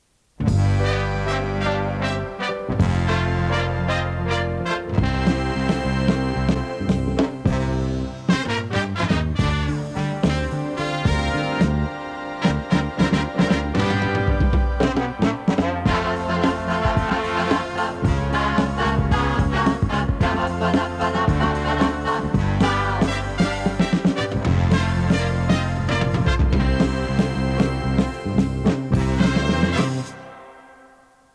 Indicatiu instrumental
Sintonia amb identificació final